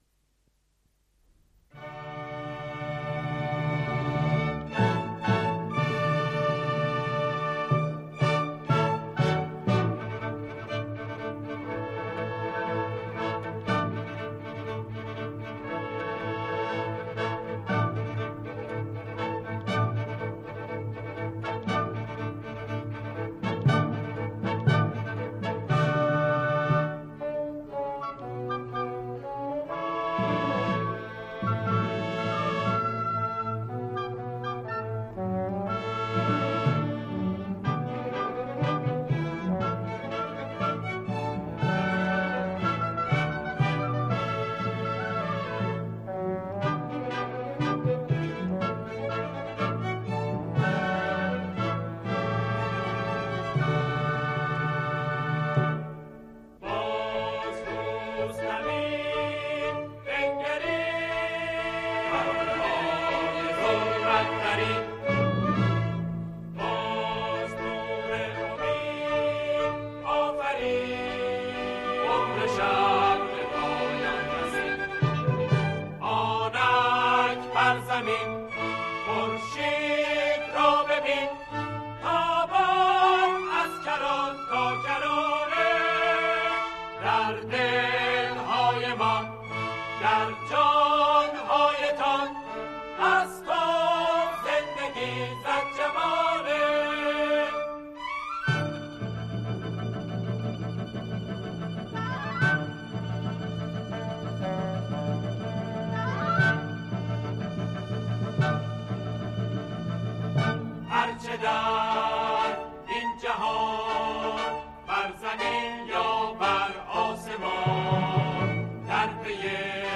ضبط شده در سال ۱۳۶۳